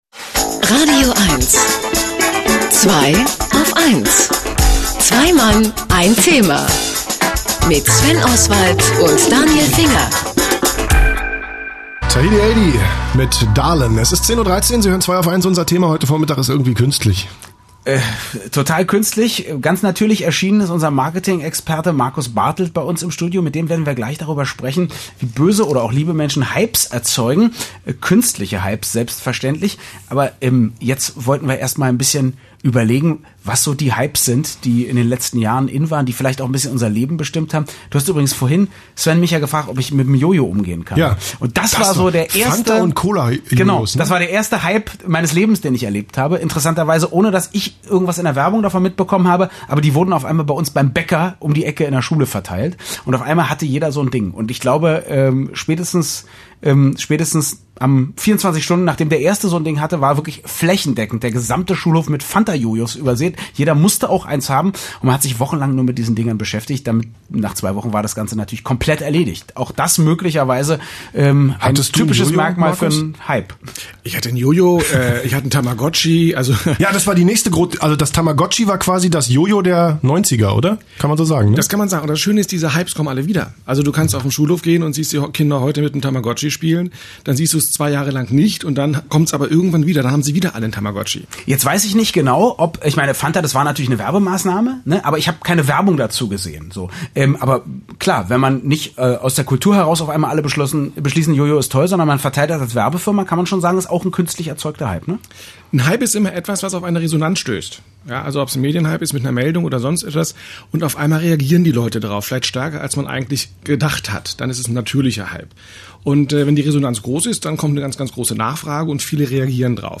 Abschließend mein drittes Interview zum Thema „künstlicher Hype“: